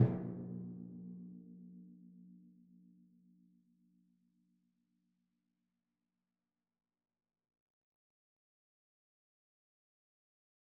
Timpani2_Hit_v3_rr1_Sum.wav